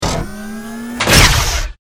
battlesuit_smalllaser.wav